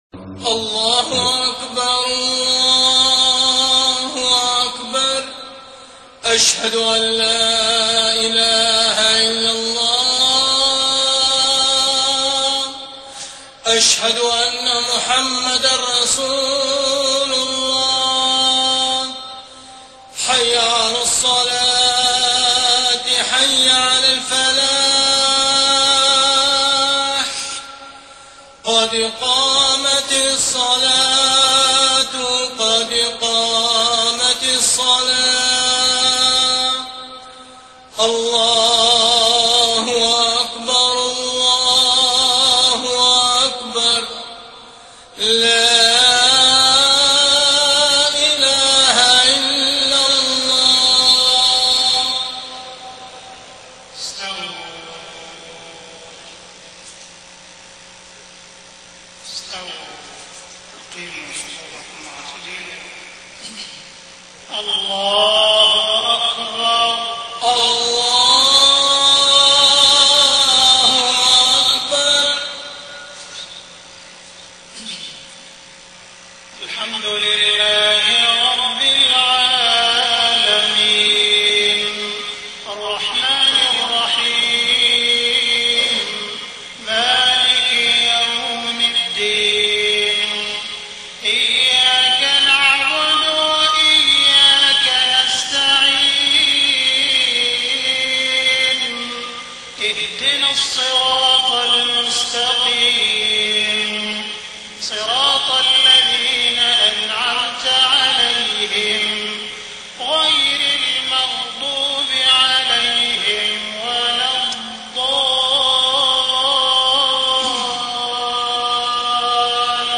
صلاة المغرب 24 محرم 1430هـ سورتي البينة و الزلزلة > 1430 🕋 > الفروض - تلاوات الحرمين